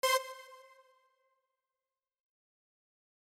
Moving onto reverb, I would recommend using a tight studio style ambience as opposed to a big large reverb.